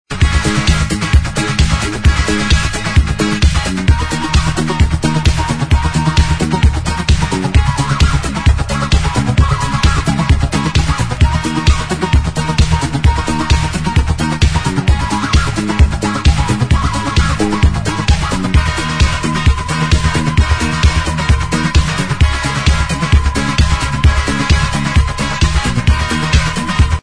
a certain guitar mix